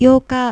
Kliknij aby ściągnąć plik dźwiękowy z wymową (zwróćcie uwagę, że podwojonego "k" nie wymawia się jak dwie głoski, tylko jako przeciągnięta jedna spółgłoska).
yooka.wav